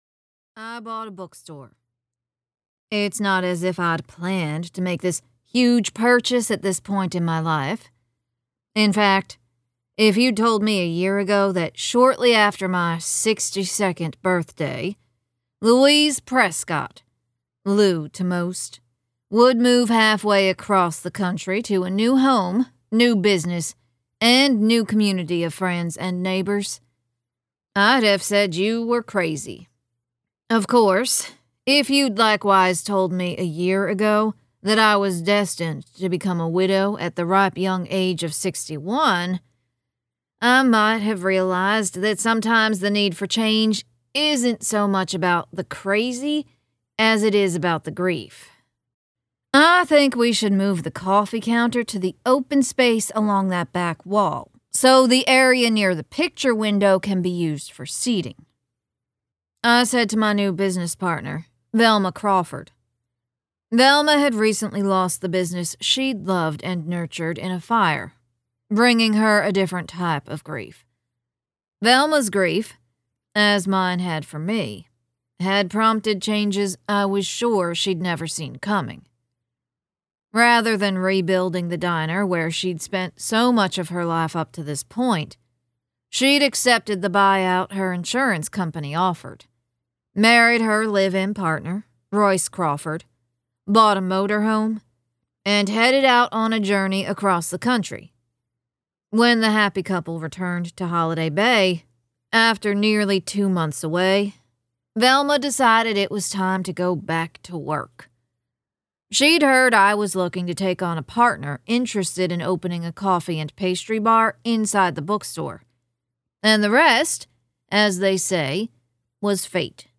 • Audiobook
Book-1-Retail-Audio-Sample-The-Bookstore-at-Holiday-Bay-Once-Upon-a-Mystery.mp3